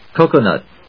音節co・co・nut 発音記号・読み方
/kóʊkən`ʌt(米国英語), ˈkəʊkʌˌnʌt(英国英語)/